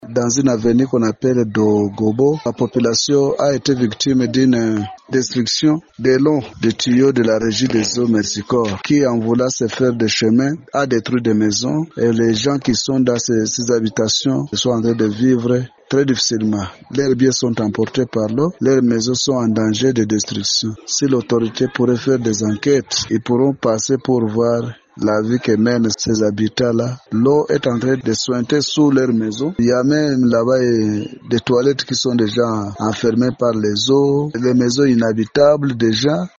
On écoute le témoignage d’un habitant de la place qui parle des dangers que courent les populations dans cette partie de la ville de Bukavu.